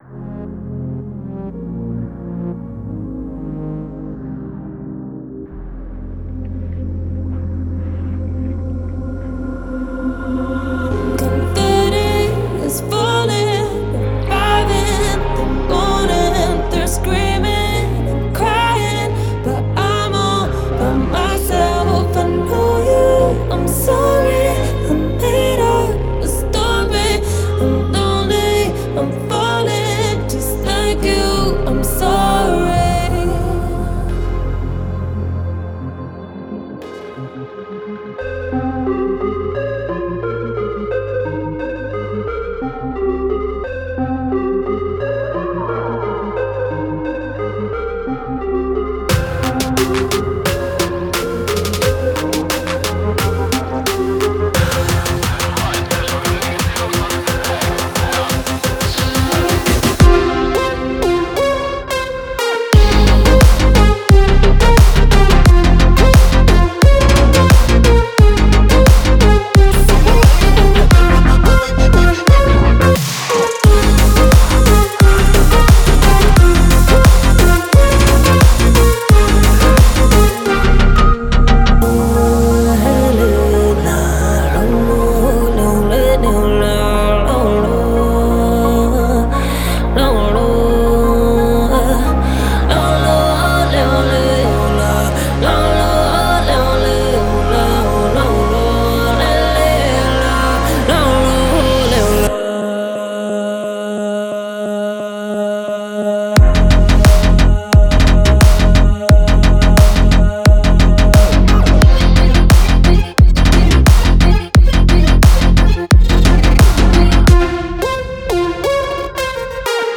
это энергичный трек в жанре EDM и поп